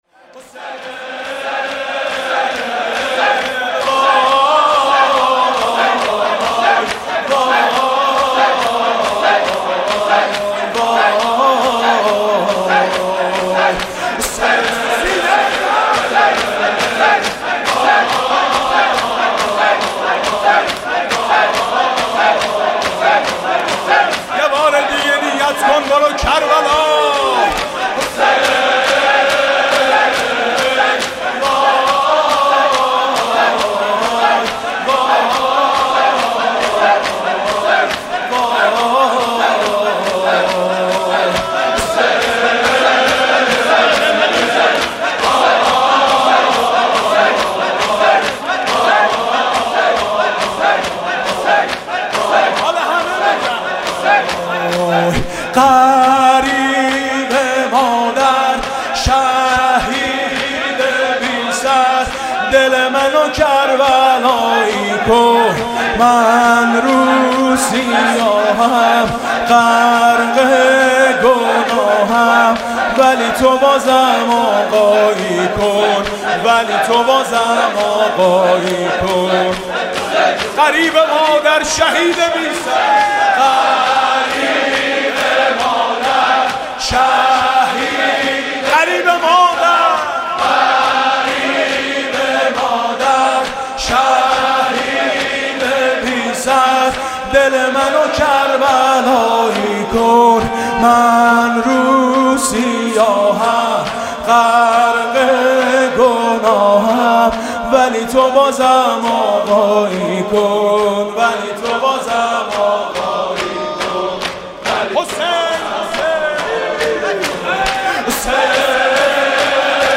شور (شب شهادت امام حسن عسکری)